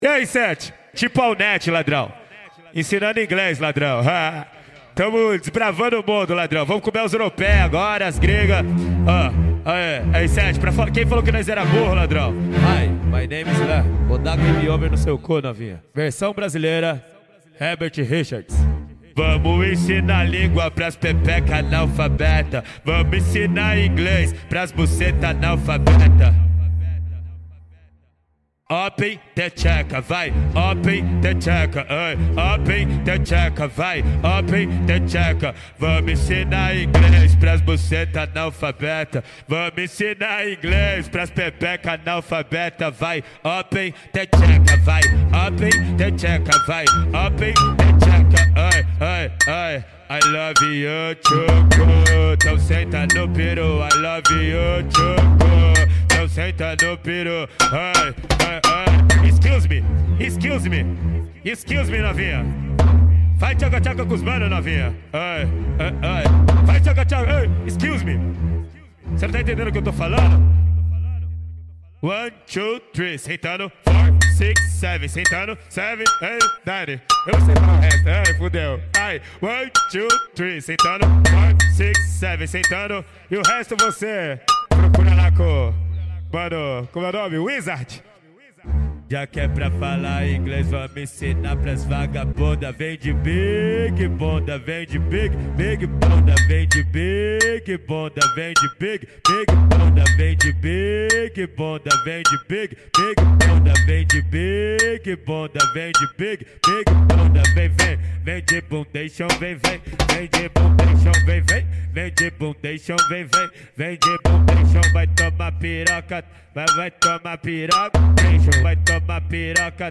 2024-03-31 19:06:51 Gênero: Funk Views